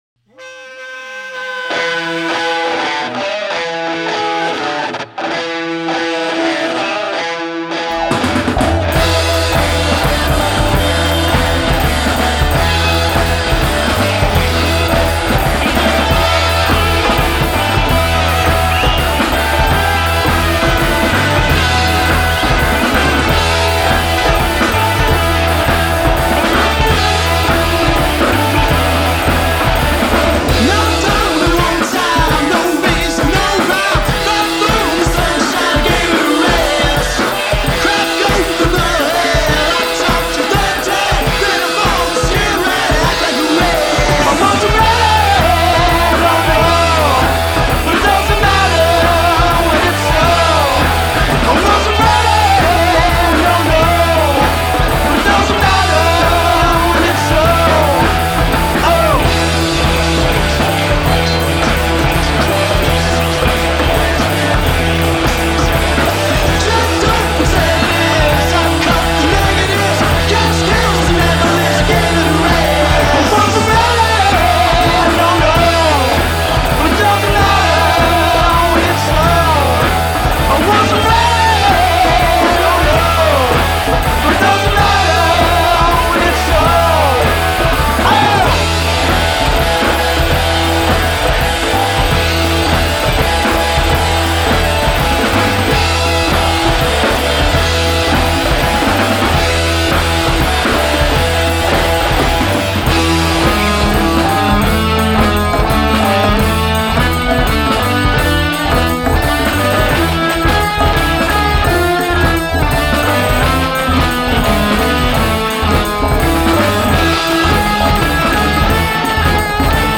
Tag: classic rock guitar
fiery new rock w/ old-school bones